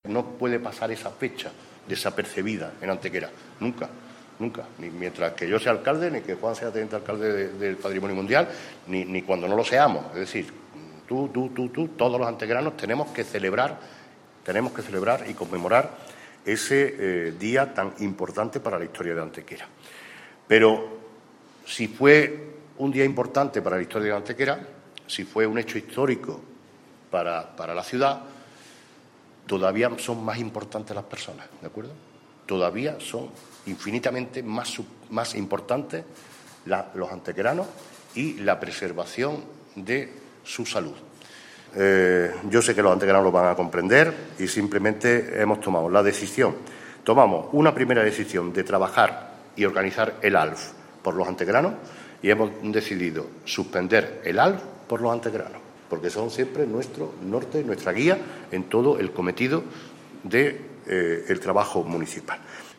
El alcalde de Antequera, Manolo Barón, y el teniente de alcalde delegado de Patrimonio Mundial, Juan Rosas, han anunciado en la mañana de hoy viernes en rueda de prensa la cancelación del Antequera Light Fest (ALF), festival de nuevas tecnologías, luz y sonido que cada año a mediados del mes de julio conmemora en nuestra ciudad la declaración del Sitio de los Dólmenes como Patrimonio Mundial de la UNESCO.
Cortes de voz